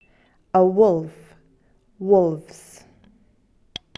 animal3 wolf
animal3-wolf.mp3